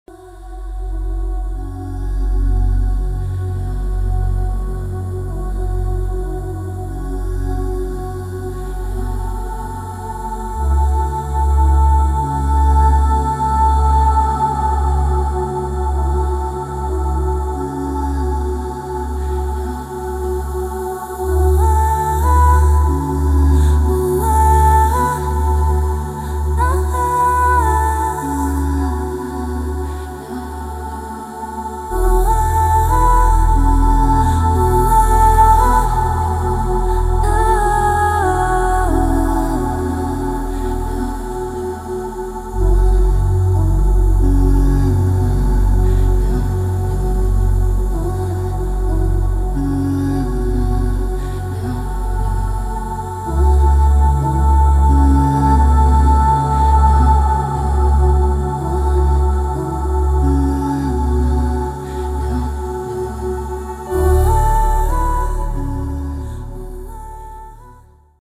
Perfect for creating deep moods and entrancing sound scapes.